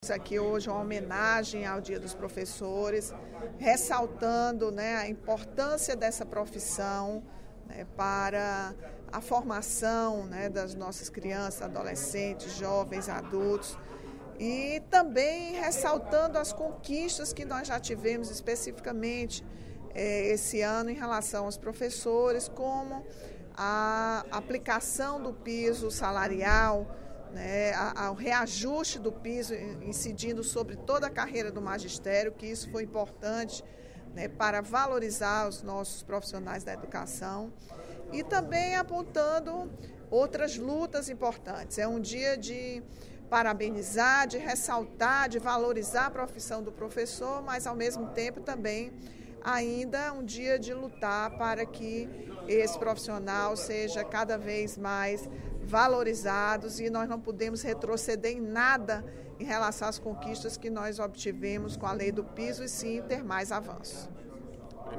A deputada Rachel Marques (PT) parabenizou, durante o primeiro expediente da sessão plenária desta quinta-feira (15/10), a categoria de professores pela celebração, hoje, do Dia do Professor.